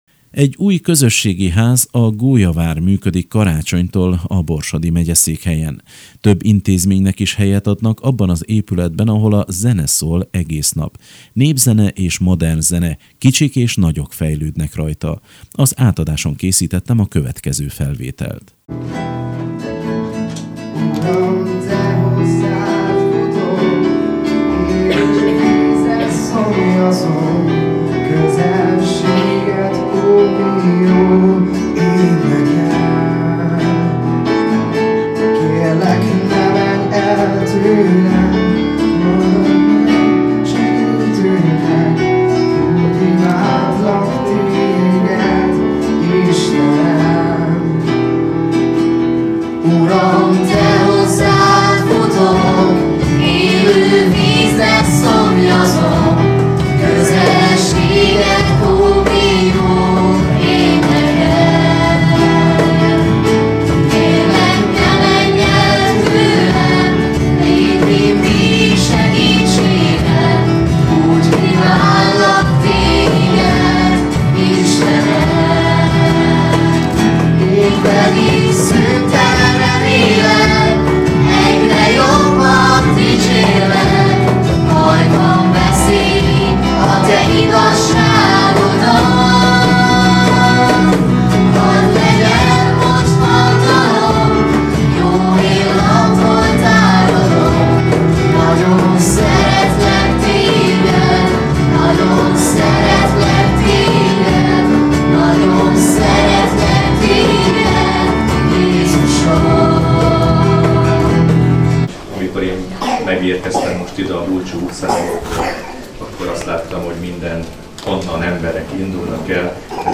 Az Európa Rádió műsorában szerepelt az iskolánk székhelyének szentelésén készült riportműsor.